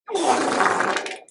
Wet Fart Meme